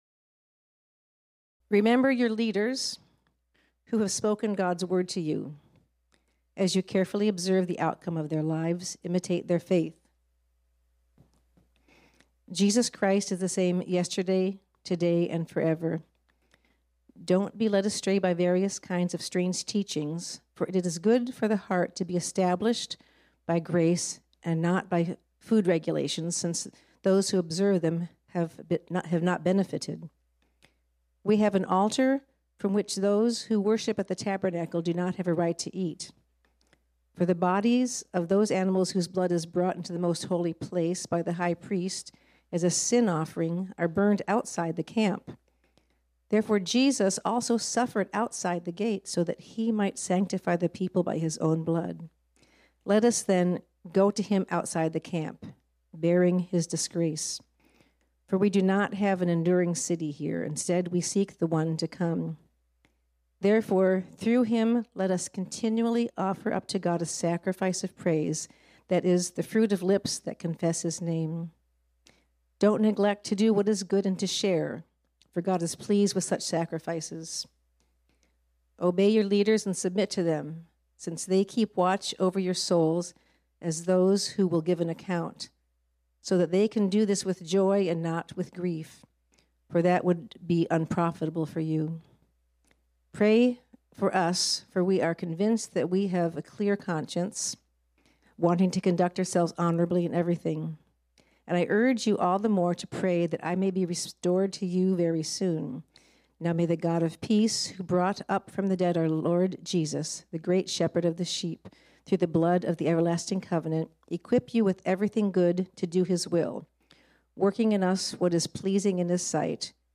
This sermon was originally preached on Sunday, March 19, 2023.